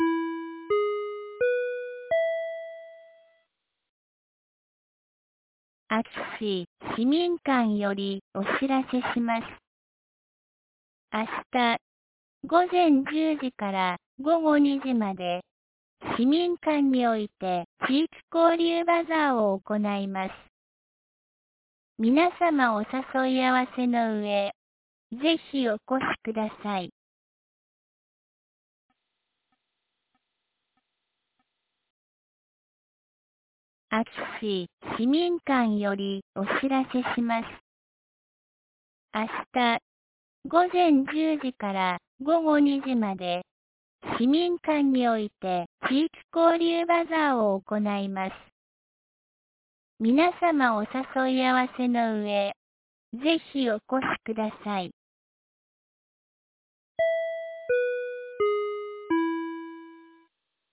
2023年02月04日 17時06分に、安芸市より全地区へ放送がありました。